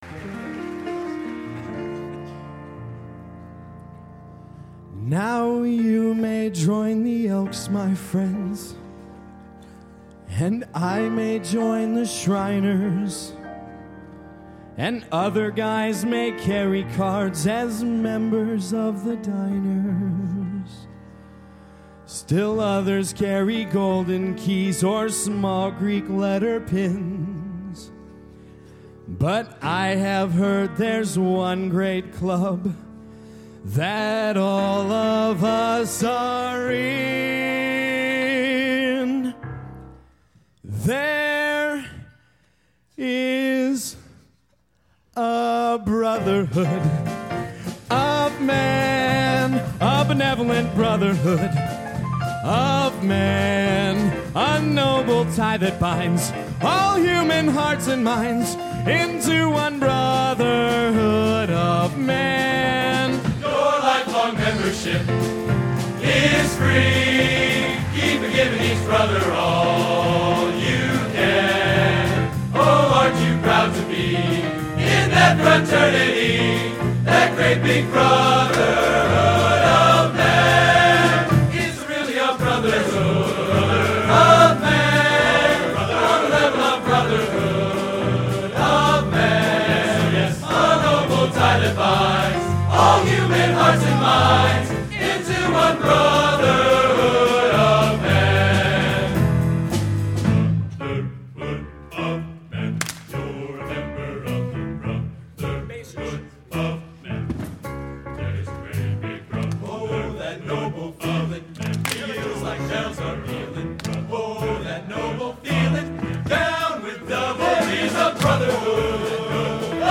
Genre: Broadway | Type: